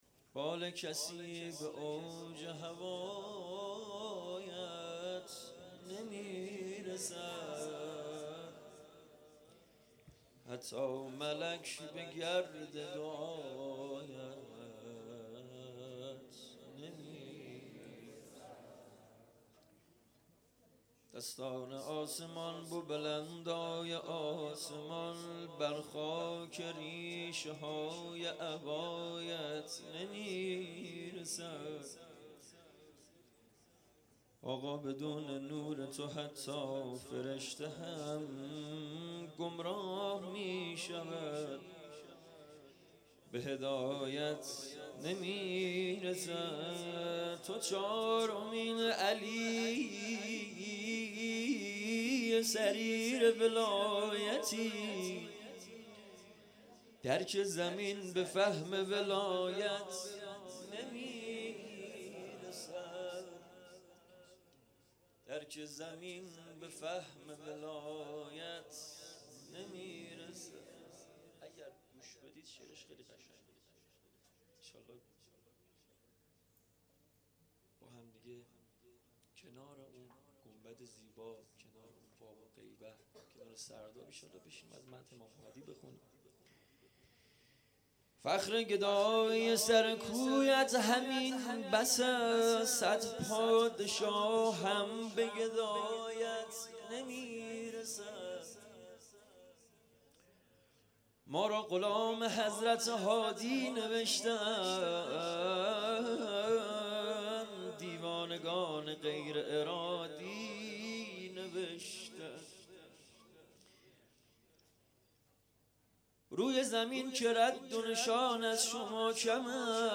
جشن ولادت امام هادی علیه السلام